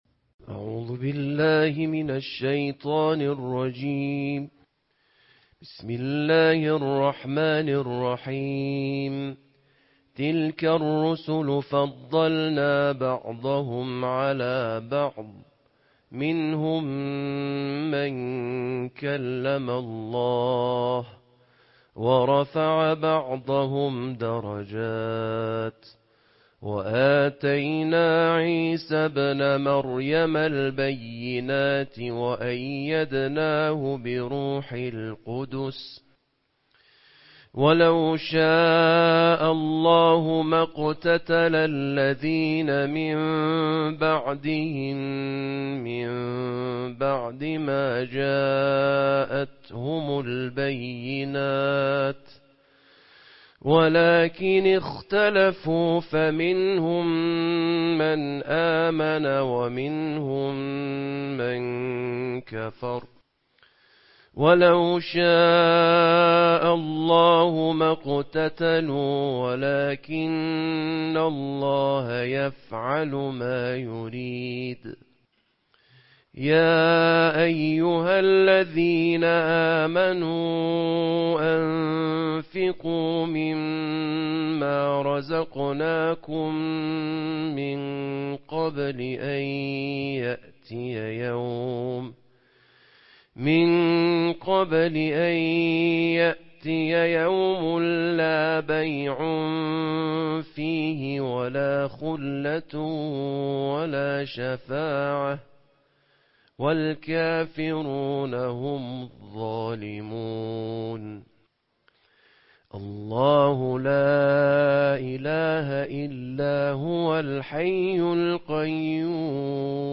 Қироати тартилии ҷузъи сеюми Қуръон бо садои қориёни байналмилалӣ